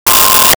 Sci Fi Beep 06
Sci Fi Beep 06.wav